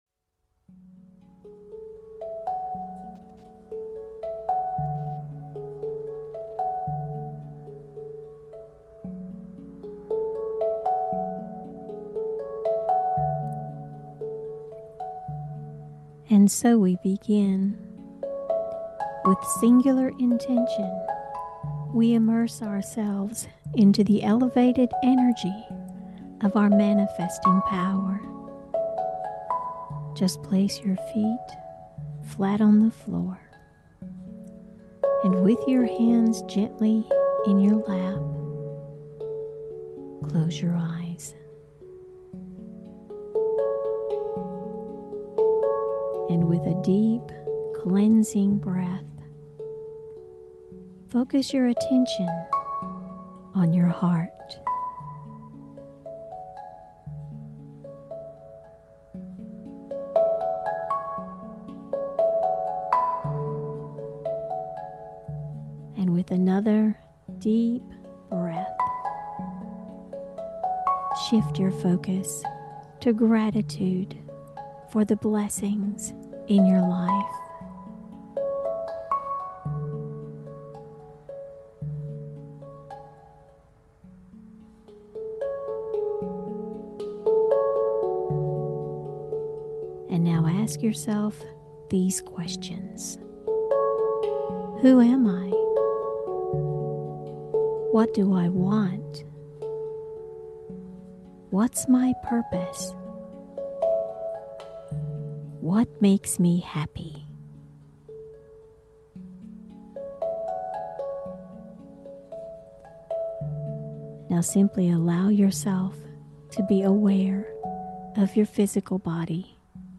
Full immersion into these guided meditations opens the way for you to bring your heart and mind into harmony/coherence and fully engage with your manifesting power – moving your beyond the analytical 3D world into your field of potentiality where all things are possible.